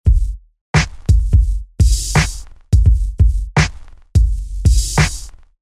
氛围朋克
描述：使用了不寻常的套件，漂亮的大音量环境鼓循环。
标签： 85 bpm Ambient Loops Drum Loops 972.88 KB wav Key : Unknown
声道立体声